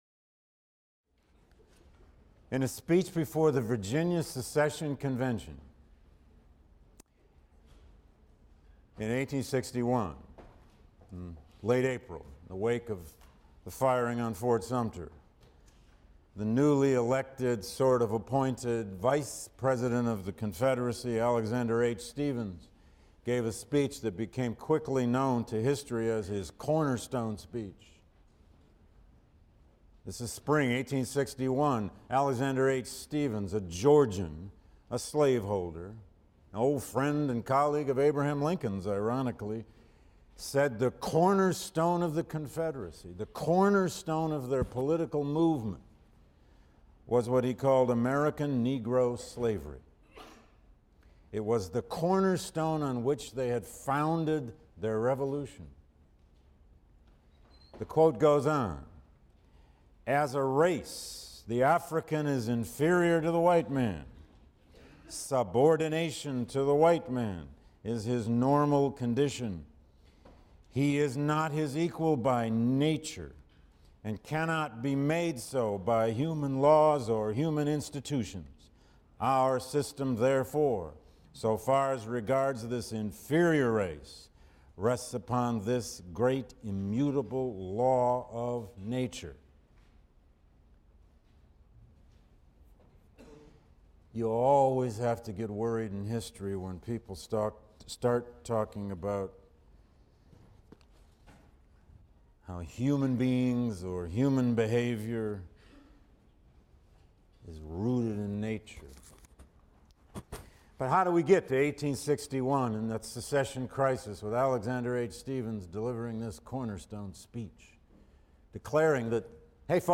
HIST 119 - Lecture 3 - A Southern World View: The Old South and Proslavery Ideology | Open Yale Courses